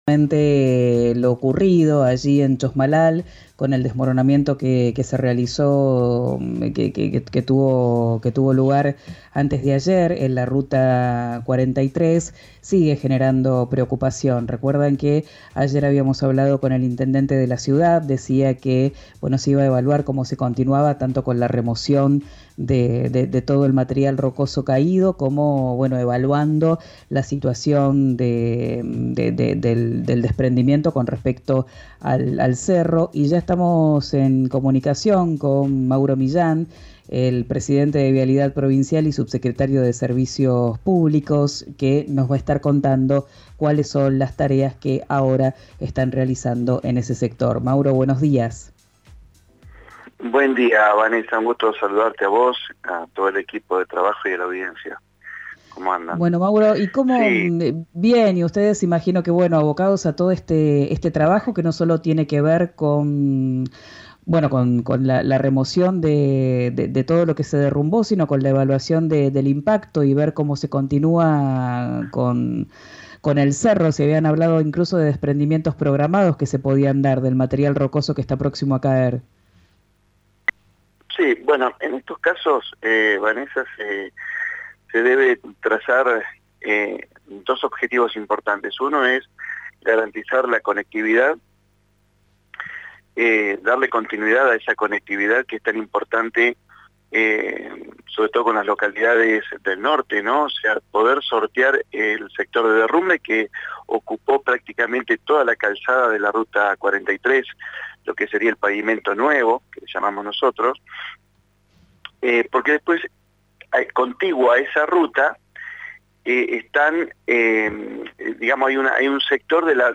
El presidente de Vialidad Provincial y subsecretario de Servicios Públicos, Mauro Millan, habló con RÍO NEGRO RADIO acerca de las ejecuciones de las tareas del equipo y del destino de los restos del derrumbe en el norte de Neuquén.